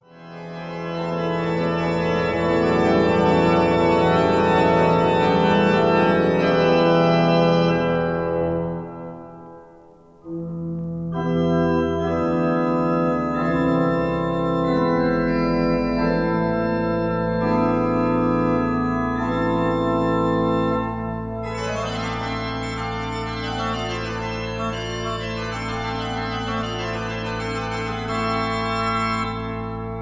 organ
St John’s Church, Lüneburg